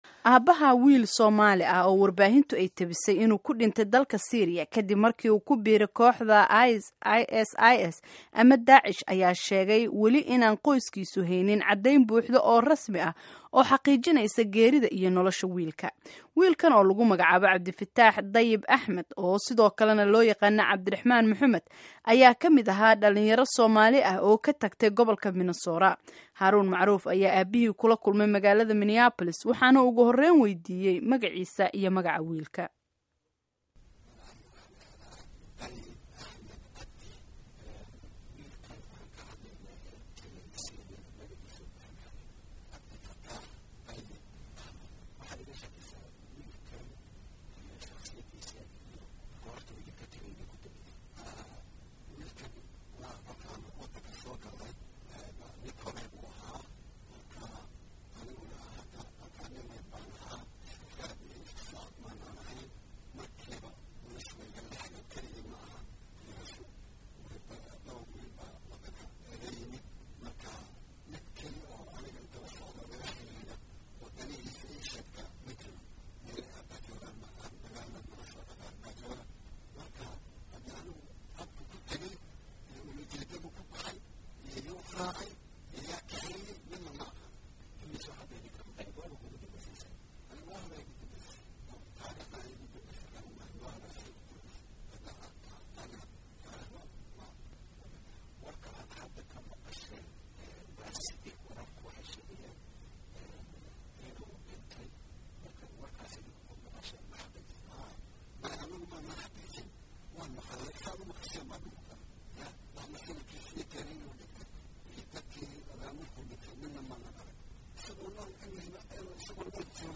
Dhageyso Wareysiga Aabaha wiilka ku Dhintay Syria